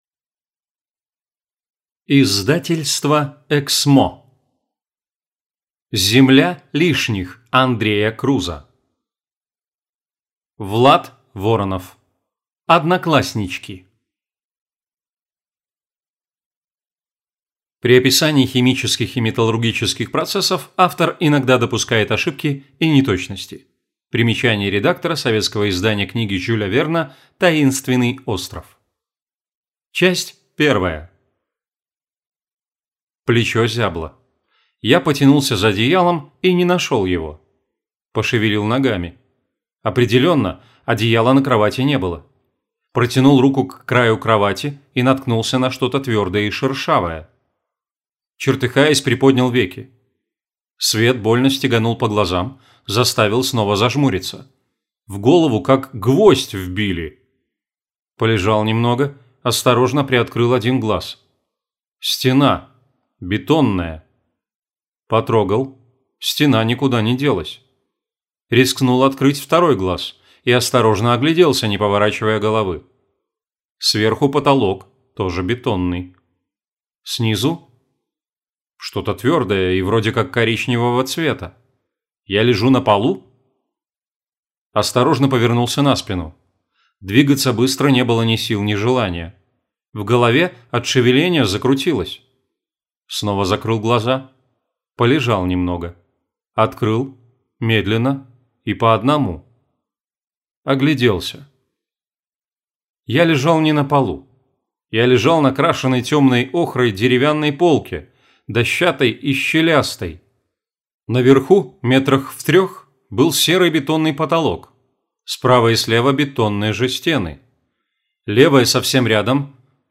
Аудиокнига Земля лишних. Однокласснички | Библиотека аудиокниг